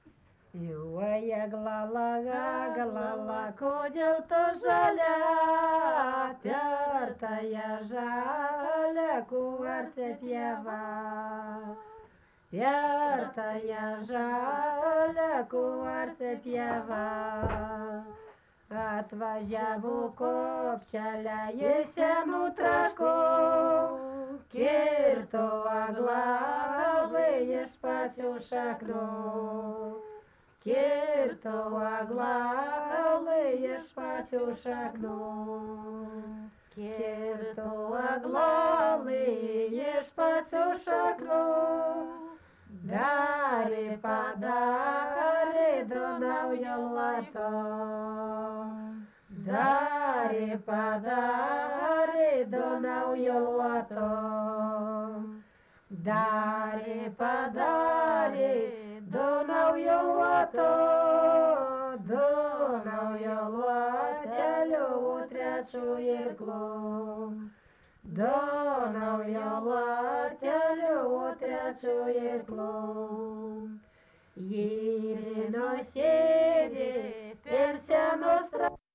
daina, vestuvių
Erdvinė aprėptis Mitriškės
Atlikimo pubūdis vokalinis